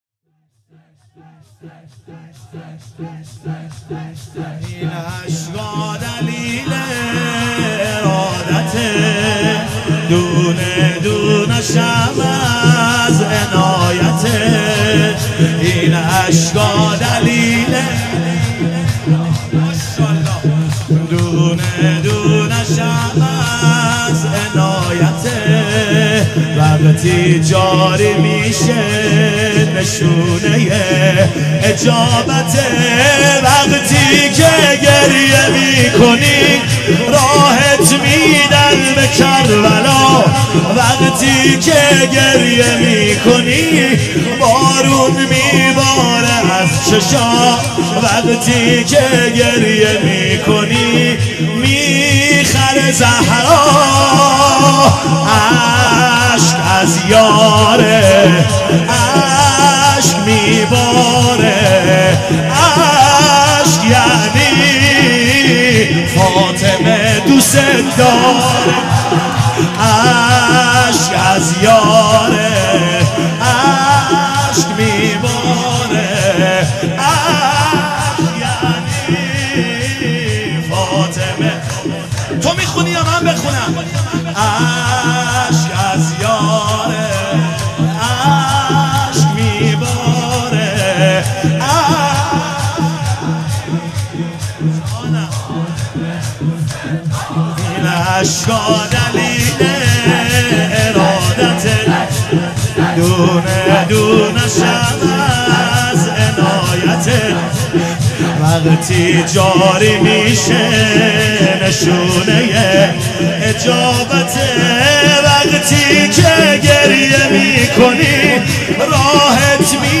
مداحی فاطمیه 1396
؛ (شور) «این اشکا دلیل ارادته